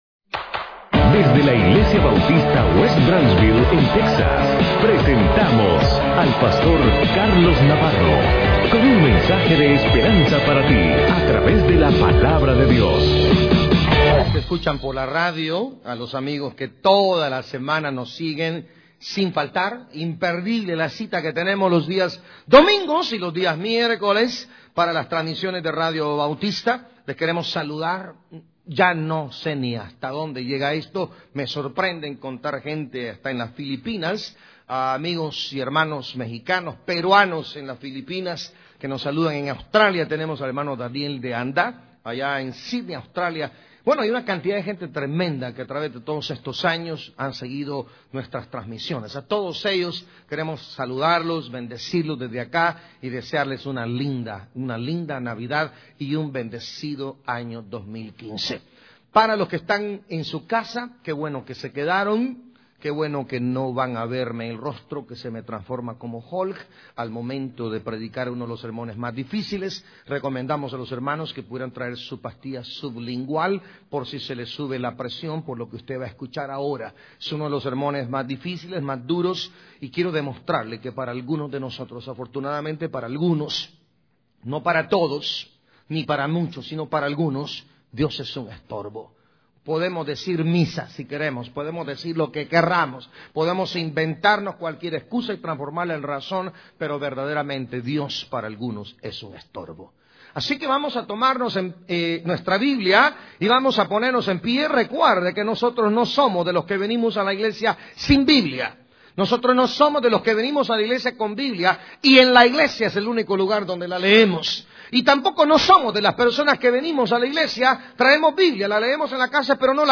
Sermones en audio y vídeo, fotografías, eventos y mucho más ¡Queremos servirle!